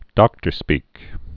(dŏktər-spēk)